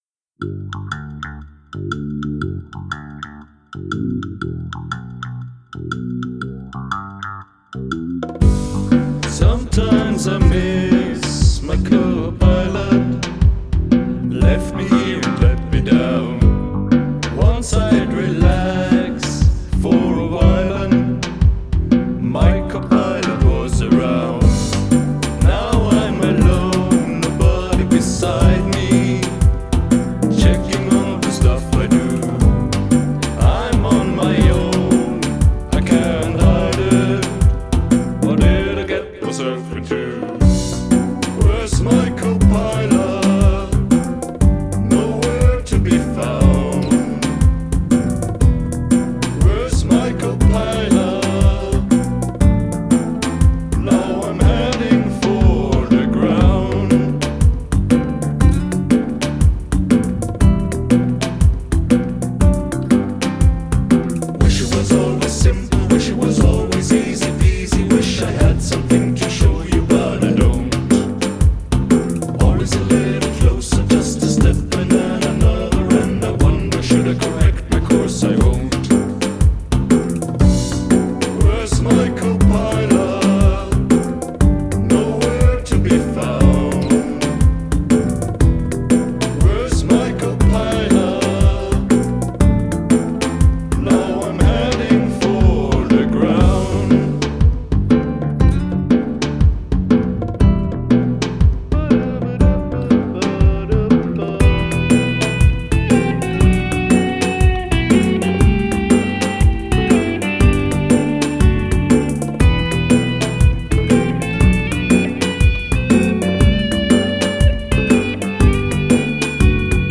Guest Lead Vocals
Solo is laid back, nice but maybe too laid back?